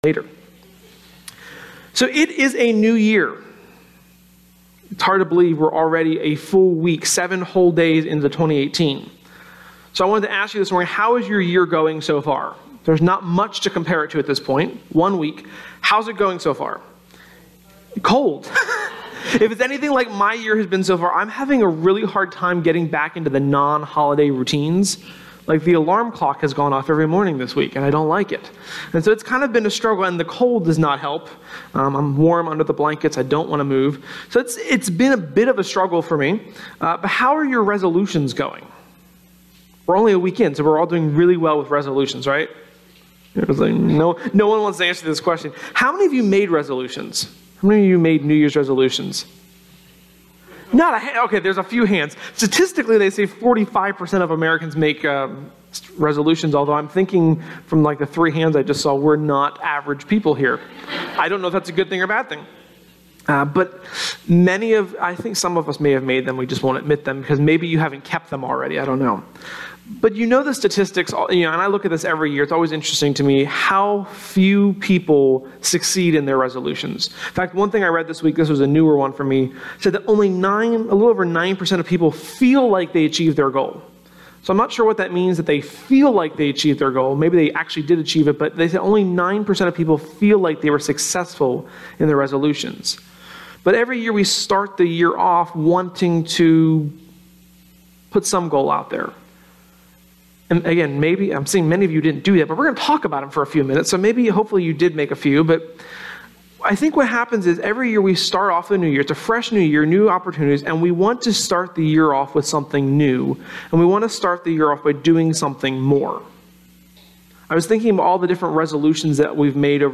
Sermon-1.7.18.mp3